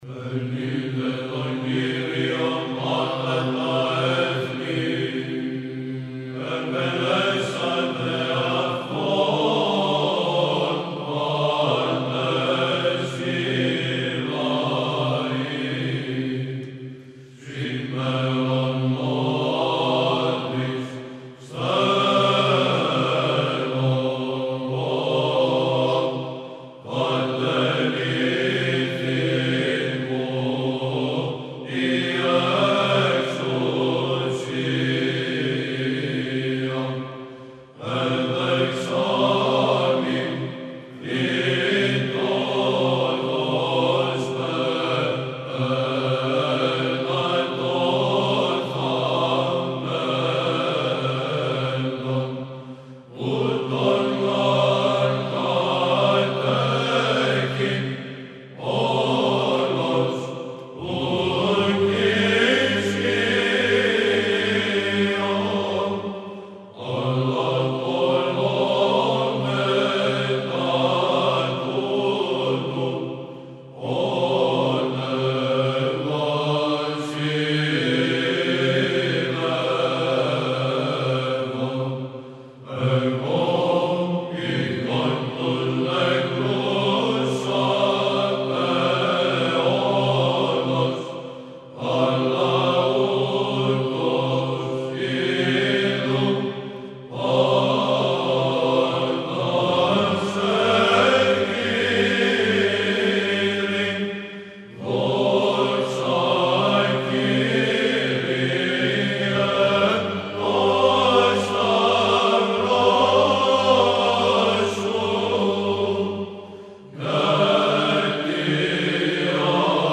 Ακούστε το από την Βυζαντινή χορωδία του Συλλόγου Μουσικοφίλων Κωνσταντινουπολιτών.